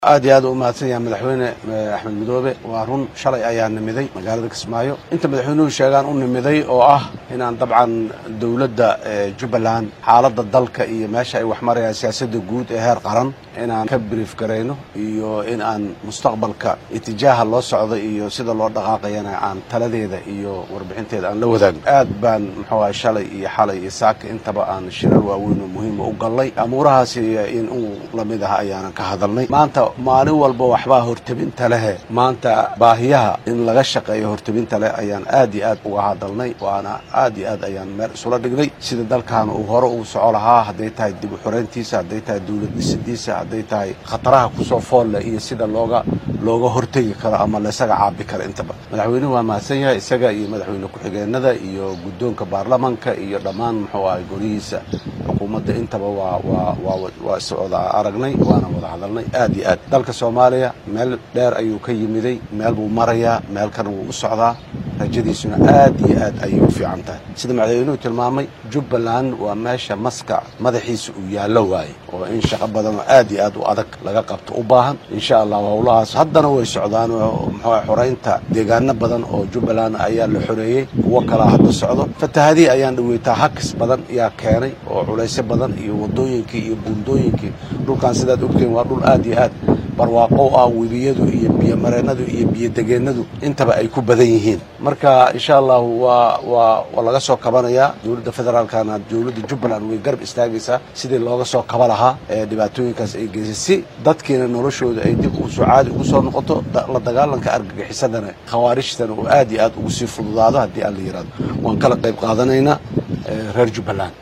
Madaxweynaha dalka Soomaaliya Xasan Sheekh Maxamuud oo shir jaraa’id ku qabtay magaalada Kismaayo ee xarunta maamul goboleedka Soomaaliyeed ee Jubbaland ayaa ka hadlay qorshaha dhammaystirka xorreynta deegaannada ku jira gacanta kooxda Al-Shabaab iyo saameynta taban ee ka dhalatay fatahaadihii roobkii deyrta ee Elino ee ka da’ay deegaannada Jubbaland.